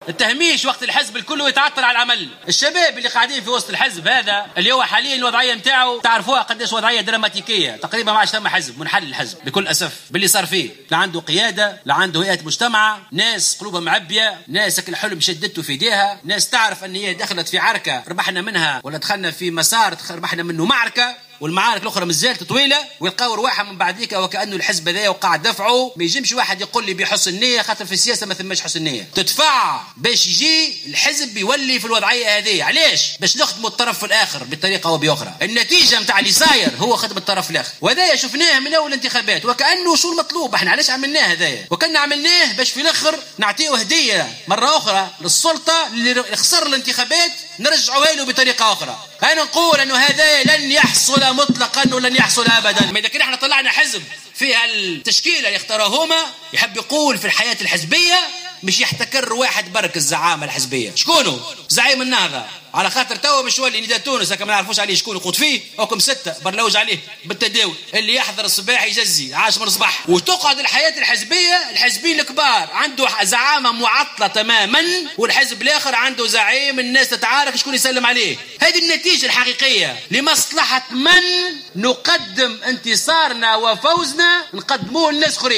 وقال خلال الملتقى الوطني للشباب تحت شعار "أي شباب نريد لتونس" إن ما يحصل داخل حزب نداء تونس من شأنه أن يقدّم "لمن خسر الانتخابات هدية"، وفق تعبيره.